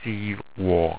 Pronounced
STEEV WOR